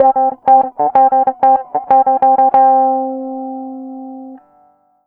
Track 13 - Clean Guitar Wah 08.wav